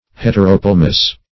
Search Result for " heteropelmous" : The Collaborative International Dictionary of English v.0.48: Heteropelmous \Het`er*o*pel"mous\, a. [Hetero- + Gr.
heteropelmous.mp3